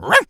pgs/Assets/Audio/Animal_Impersonations/dog_small_bark_03.wav
dog_small_bark_03.wav